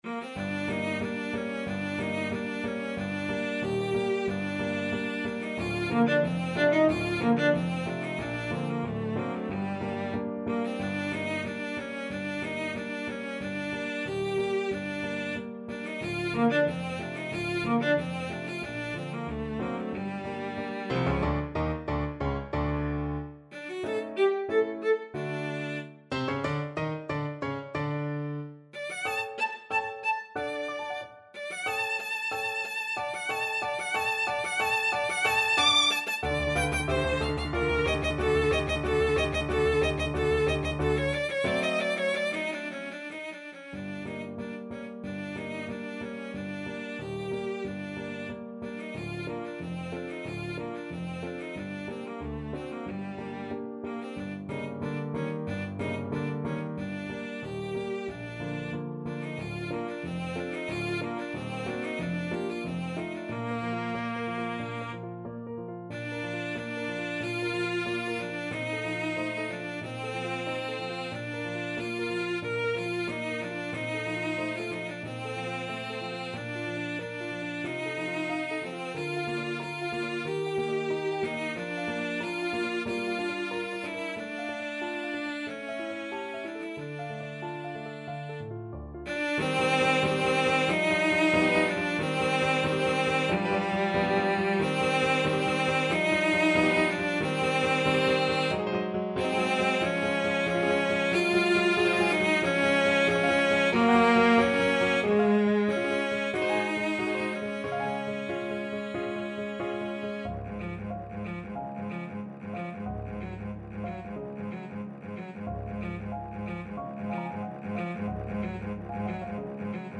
Free Sheet music for Cello
Bb major (Sounding Pitch) (View more Bb major Music for Cello )
Allegro grazioso = 92 (View more music marked Allegro)
2/4 (View more 2/4 Music)
Classical (View more Classical Cello Music)